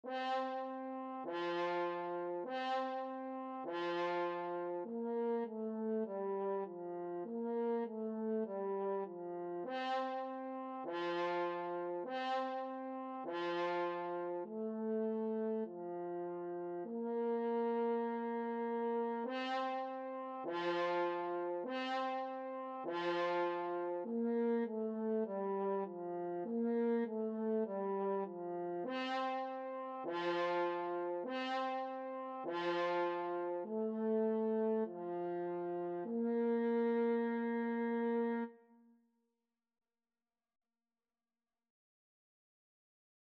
4/4 (View more 4/4 Music)
F4-C5
French Horn  (View more Beginners French Horn Music)
Classical (View more Classical French Horn Music)